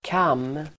Uttal: [kam:]